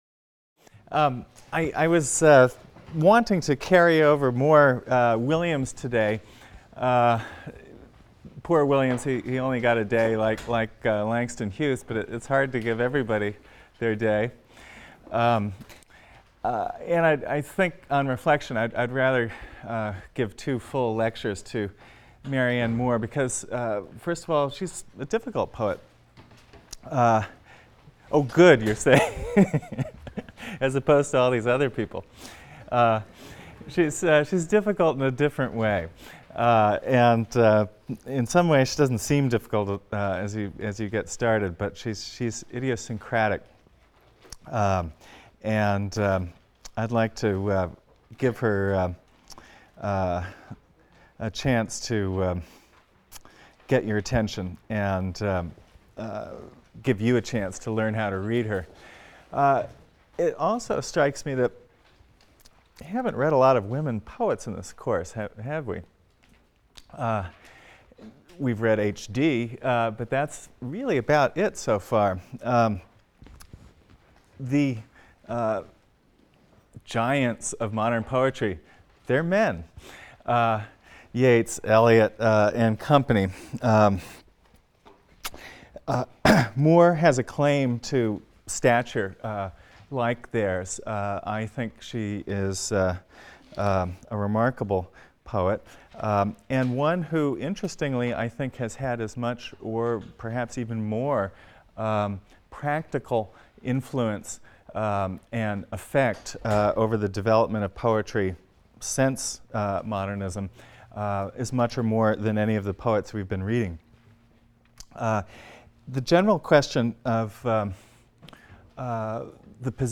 ENGL 310 - Lecture 17 - Marianne Moore | Open Yale Courses